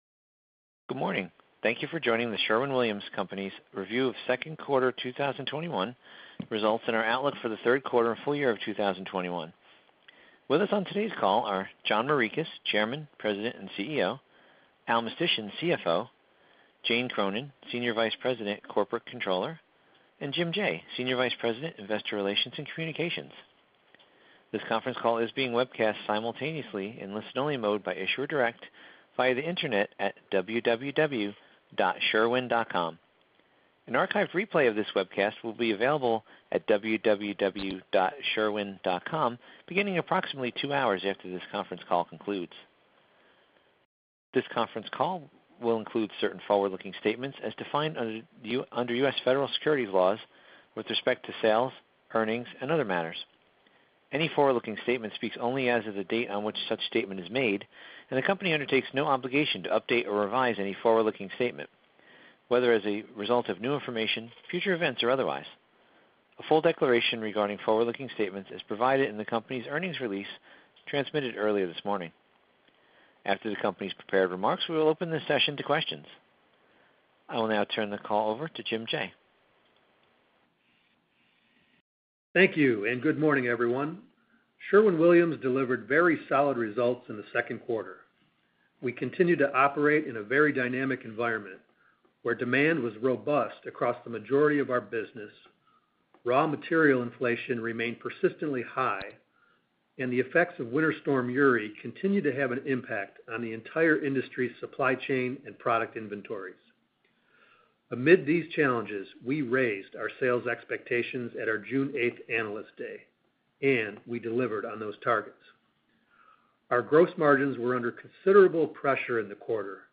Sherwin-Williams - Sherwin-Williams Second Quarter 2021 Financial Results Conference Call